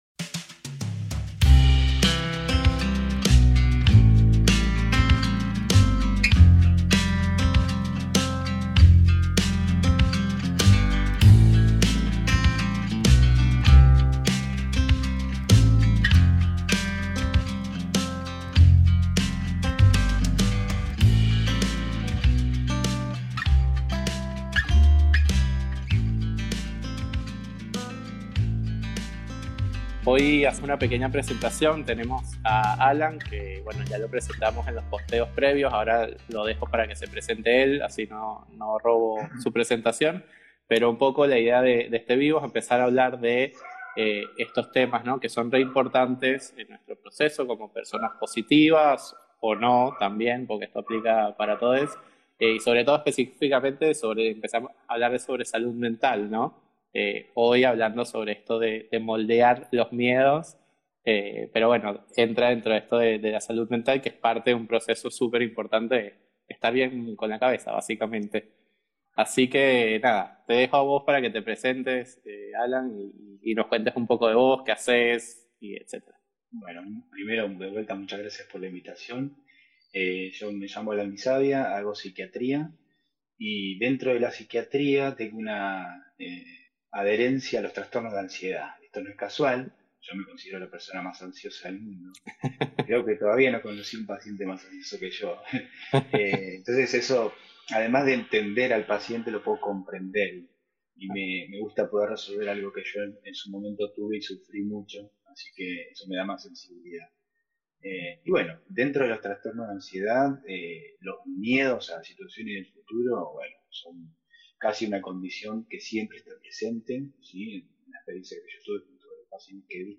Audio del IG LIVE